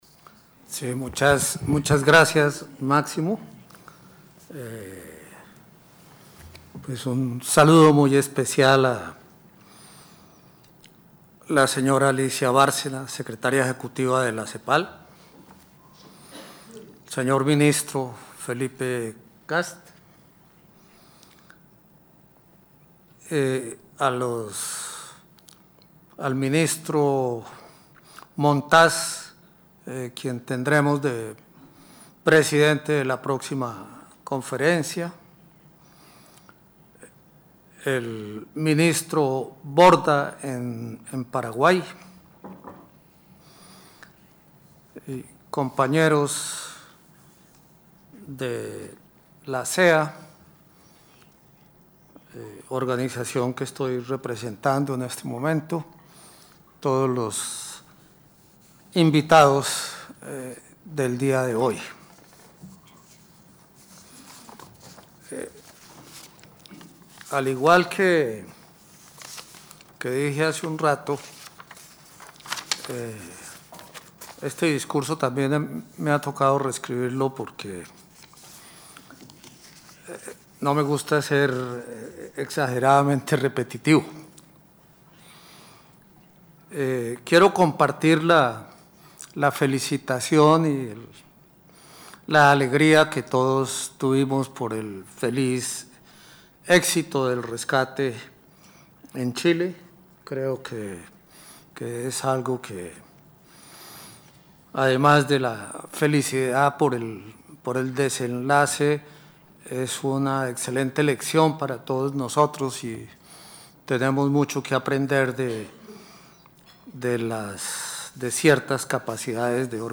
Por su experiencia en la temática y liderazgo en foros como la Comisión Estadística de Naciones Unidas y la Conferencia Estadística de las Américas, el Director del DANE, fue invitado como conferencista en el panel de discusión sobre los retos en la construcción y sostenimiento de un sistema estadístico moderno.
Para escuchar la intevrvención del director del DANE, Héctor Maldonado,